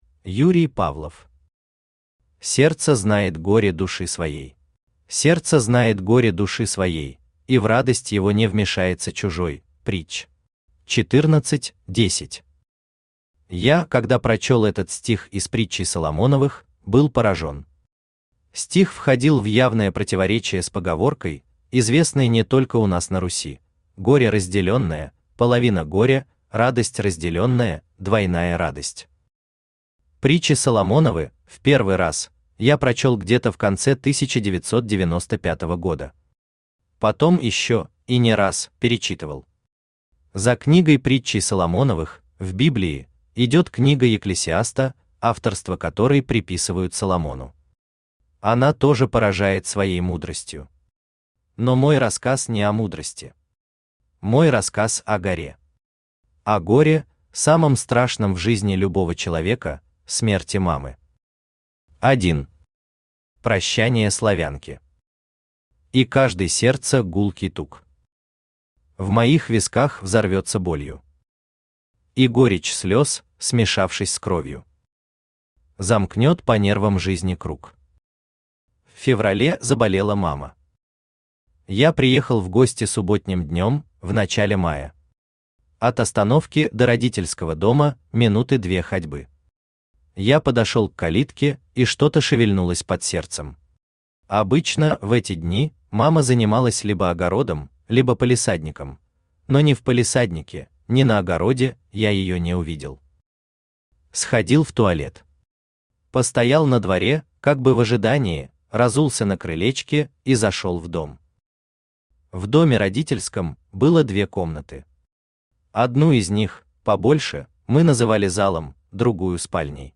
Аудиокнига Сердце знает горе души своей | Библиотека аудиокниг